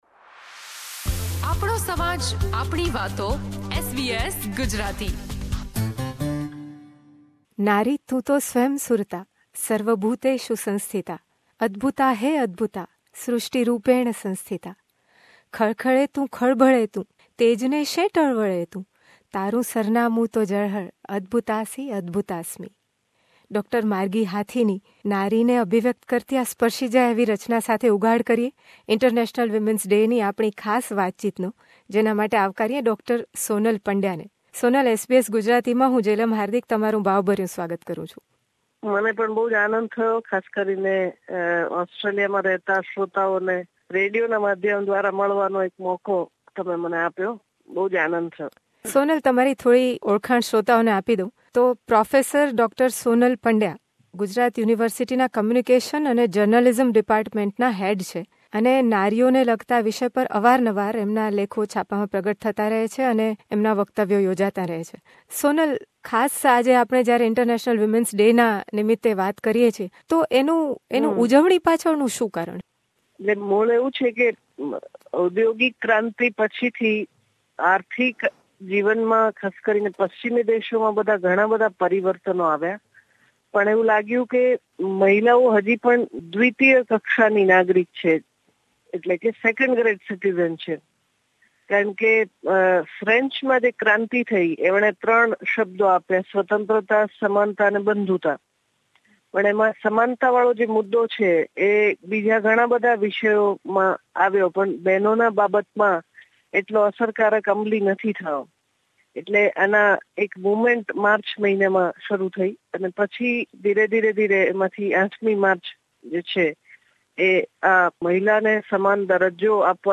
In this conversation, she also talks about the challenges faced by women in India versus Indian women living abroad.